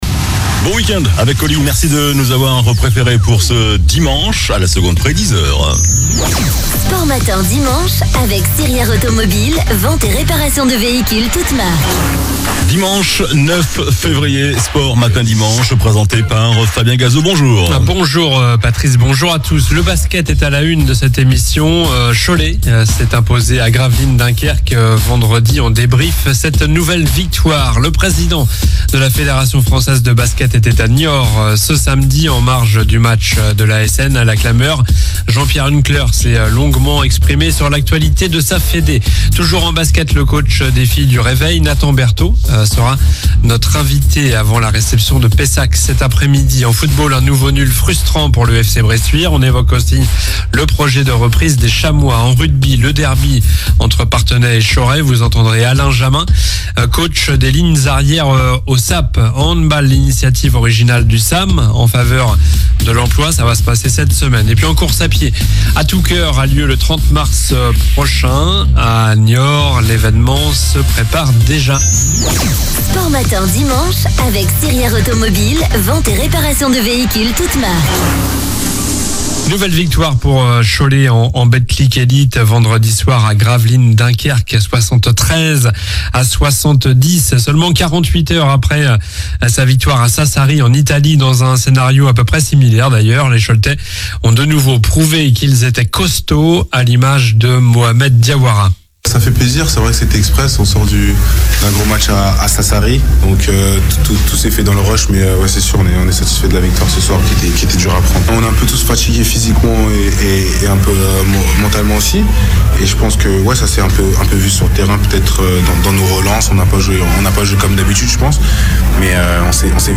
Le sport près de chez vous